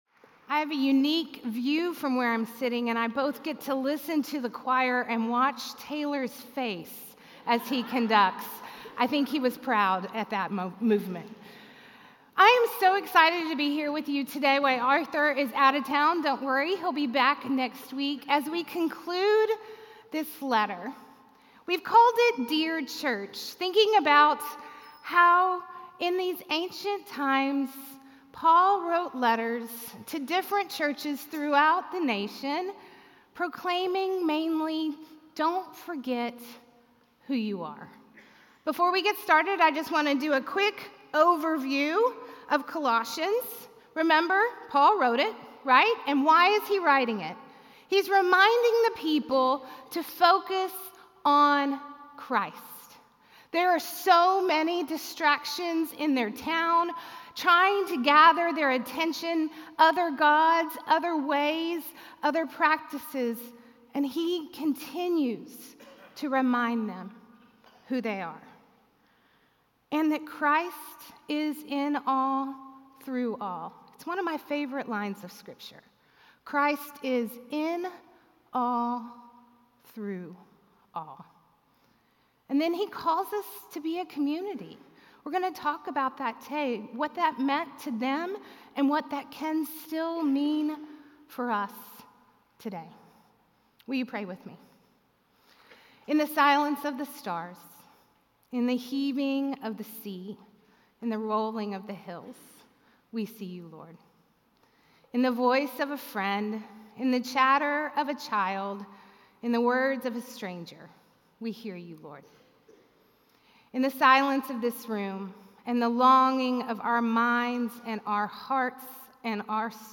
A message from the series "Dear Church."